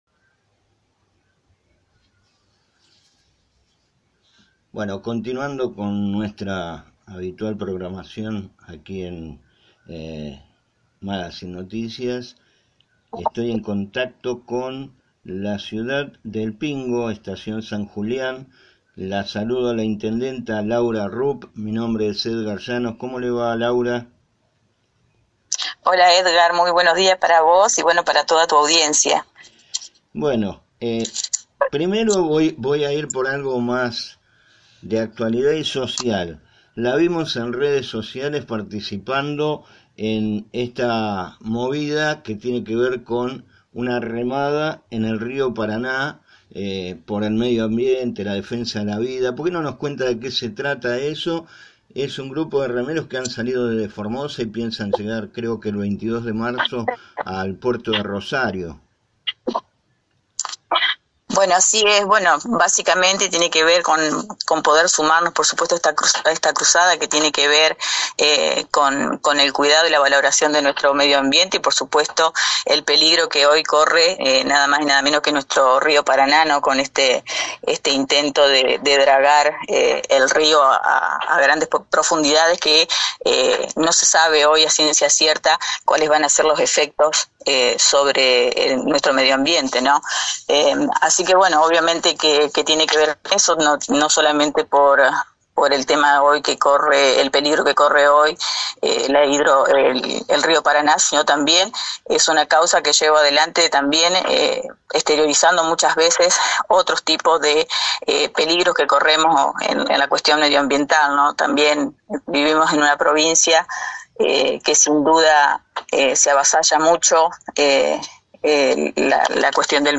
Entrevistamos a la intendenta de la ciudad de El Pingo, Estación San Julián, Laura Rupp.
Laura-Rupp-Intedententa-de-El-Pingo-ER.mp3